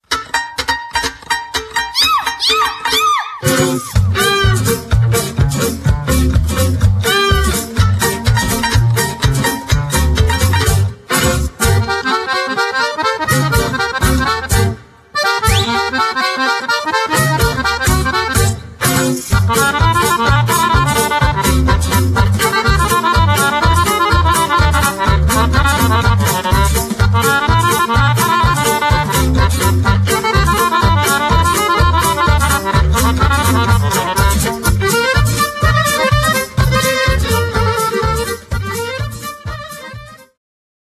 gitara guitar
akordeon accordion
skrzypce violin
kontrabas double bass
instr. perkusyjne percussions